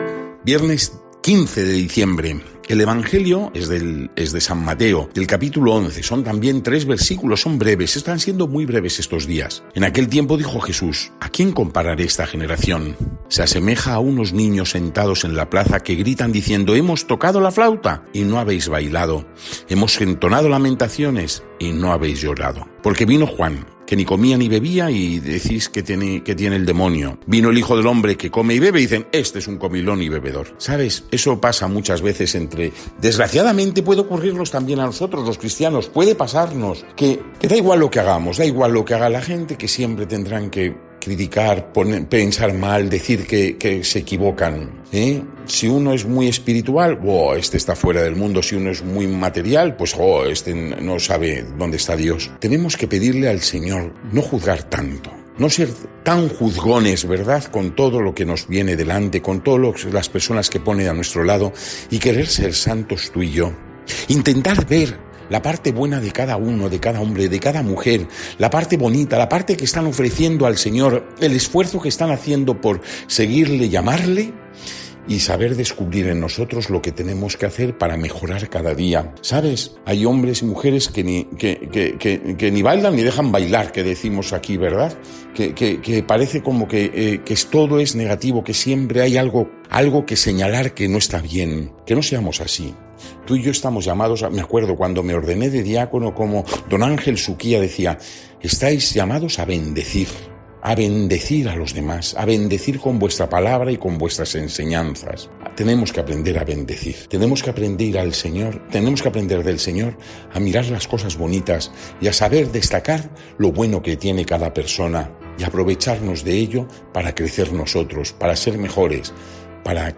Evangelio del día